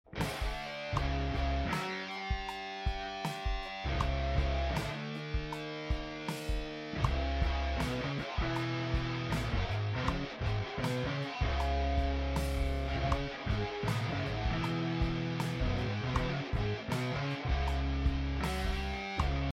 Play bass like its a guitar